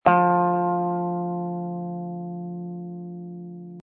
descargar sonido mp3 guitarra